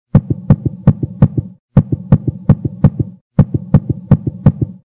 zvuk-serdcebienija_005.mp3